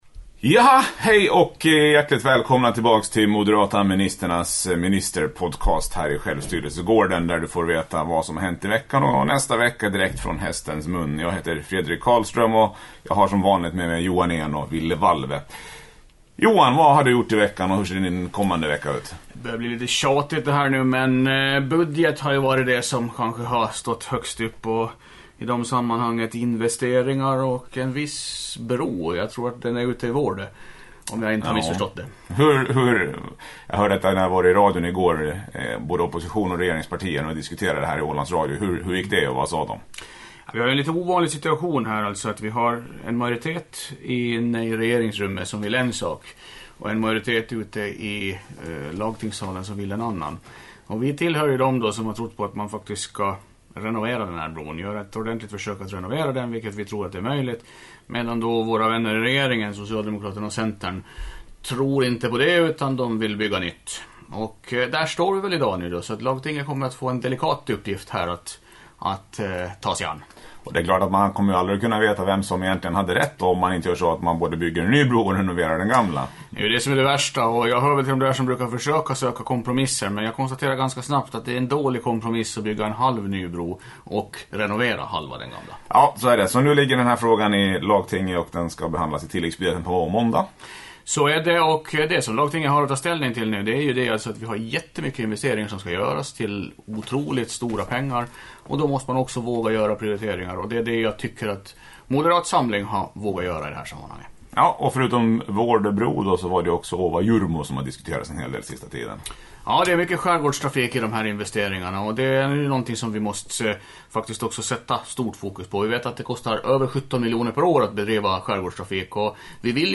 Lite rörigt idag med telefoner som ringer, borrhammare som låter men annars tre glada ministrar som sammanfattar lite av vad som hänt i veckan och vad som komma skall.